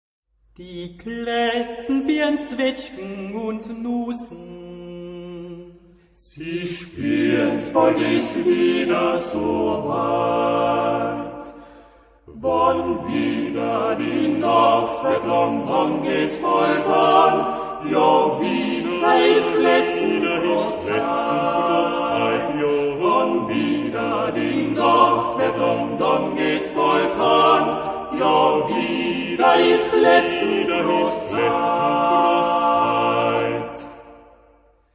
Epoque: 20th century
Genre-Style-Form: Secular ; Popular
Mood of the piece: narrative ; humorous
Type of Choir: TTBB  (4 men voices )
Tonality: B flat major